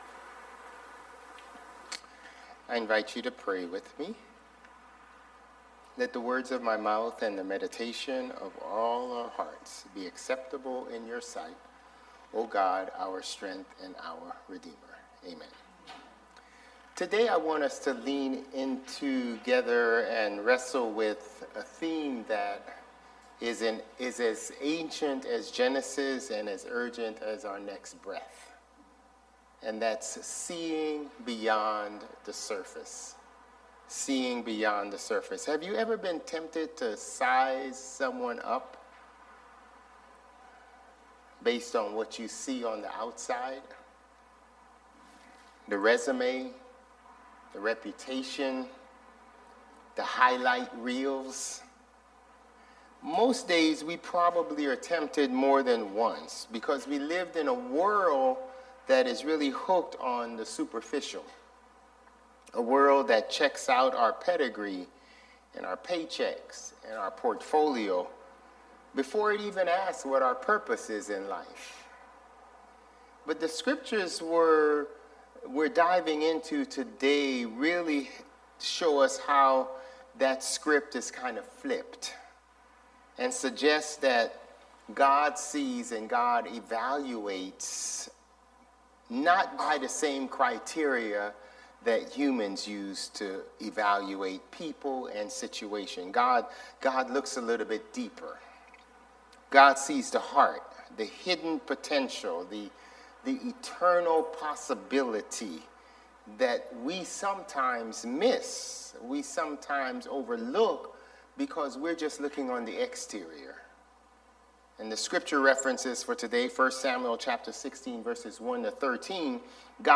March 15 Worship